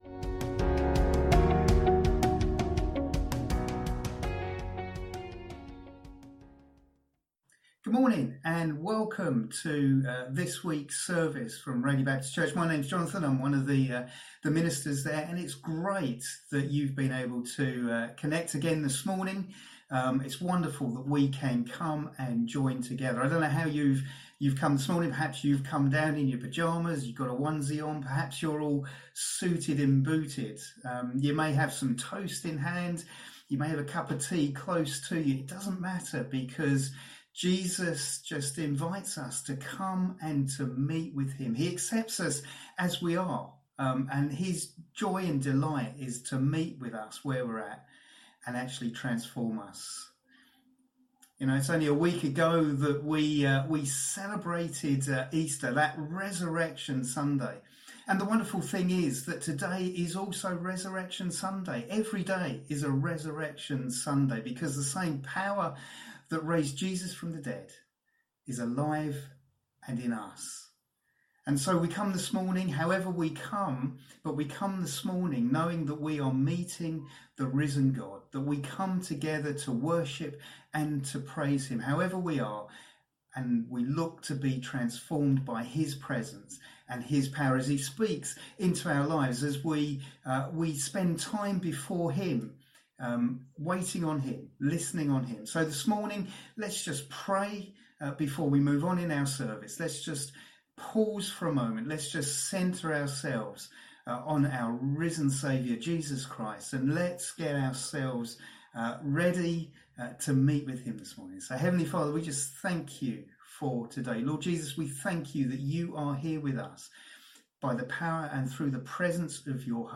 A message from the series "Faith for Lifes Journey."